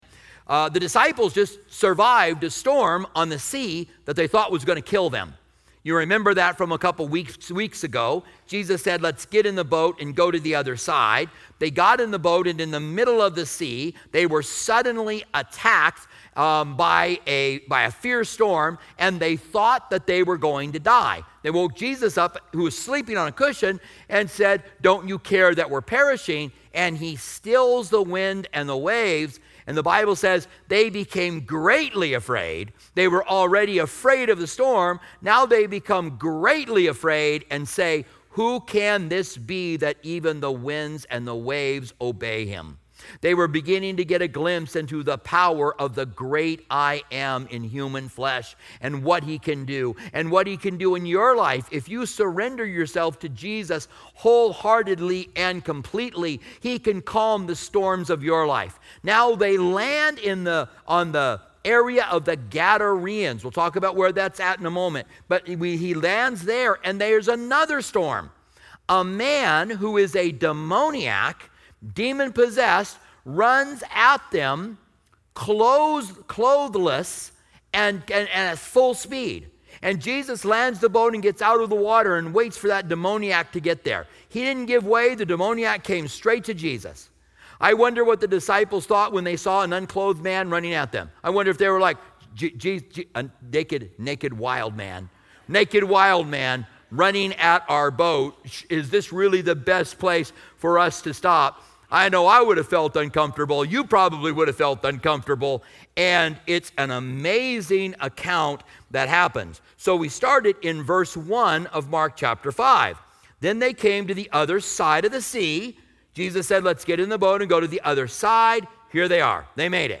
Closing Prayer